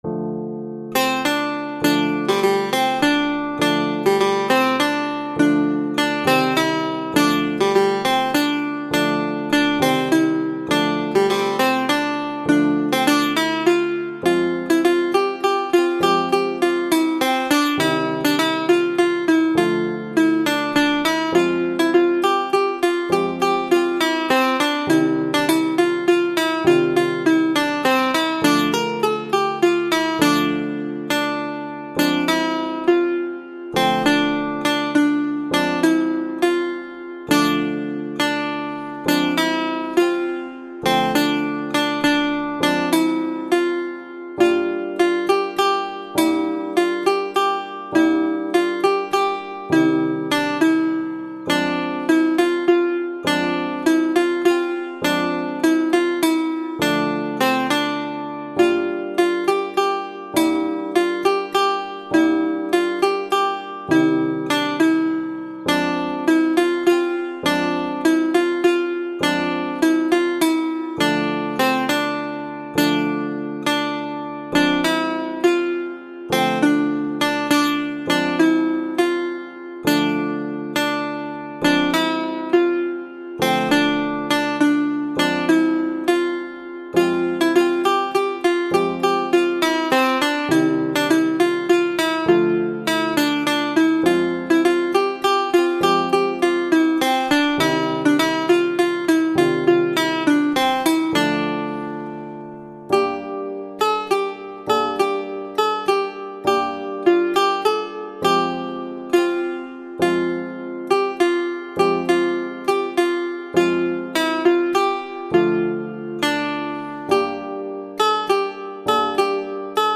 سطح : متوسط
سنتور